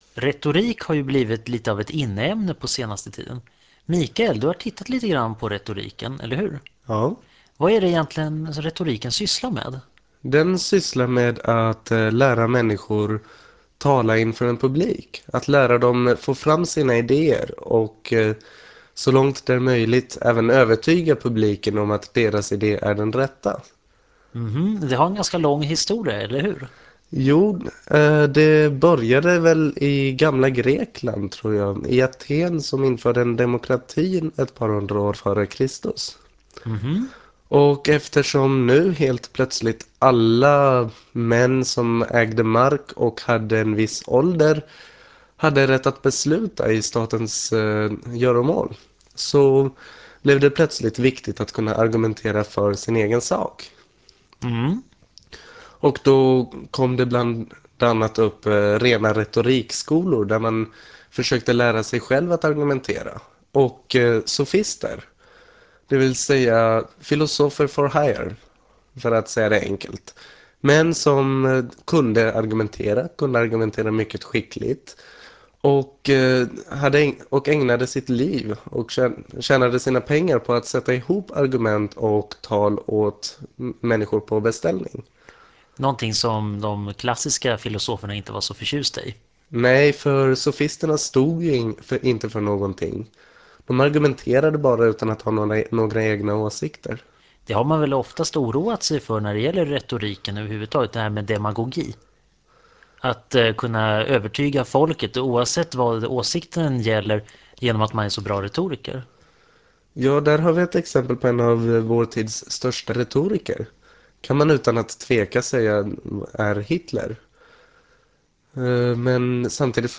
Retorik Det handlar om den gamla traditionen att tala inför publik sett ur ett historiskt perspektiv. Föredraget handlar också om kämparna på ordets slagfält.
Föredraget handlar om filosofi och sänds januari 1999 i Etervåg.